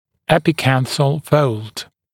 [ˌepɪ’kænθl fəuld][ˌэпи’кэнсл фоулд]эпикантус, веконосовая складка